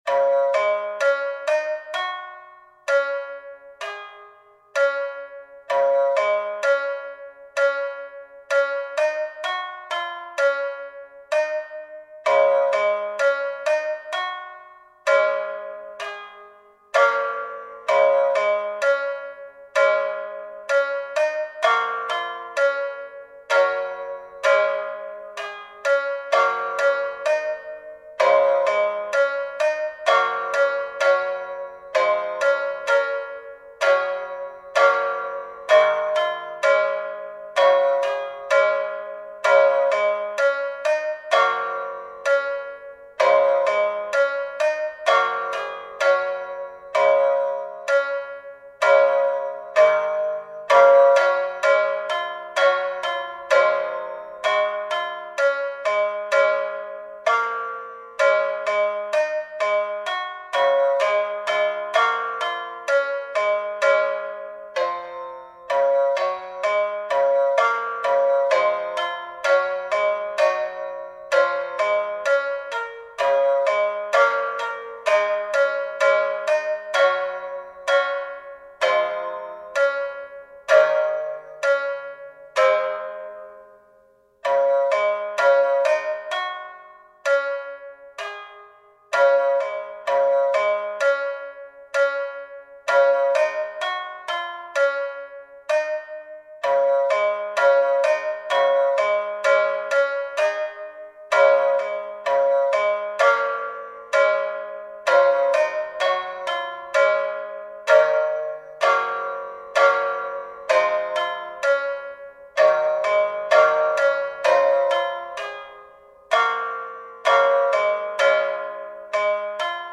【編成】三絃３ 三味線だけを用いた作曲に挑戦してみました。
曲は長調で明るい仕上がりですが、どこか切ない響きも感じます。